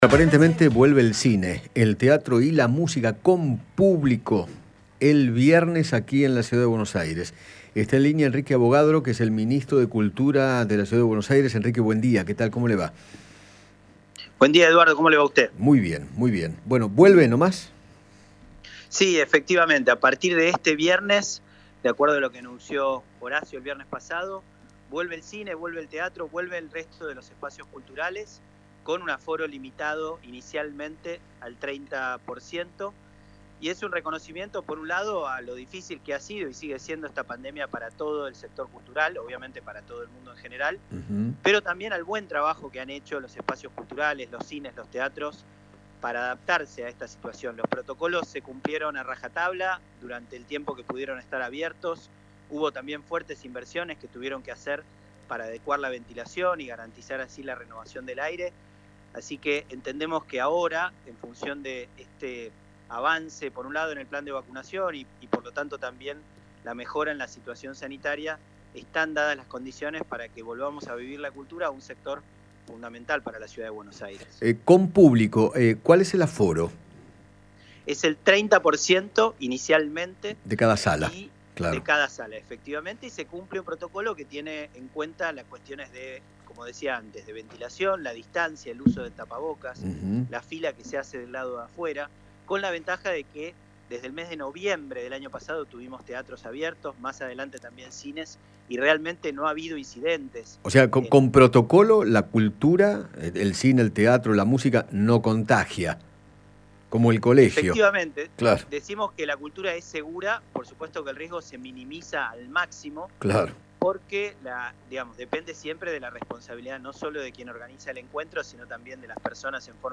Enrique Avogadro, ministro de Cultura de la Ciudad, habló con Eduardo Feinmann sobre la reapertura de varias actividades y explicó cómo se implementará en la ciudad. Además, se refirió a la situación del sector de trabajadores de la cultura.